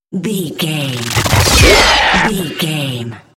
Whoosh metal sword creature
Sound Effects
dark
intense
whoosh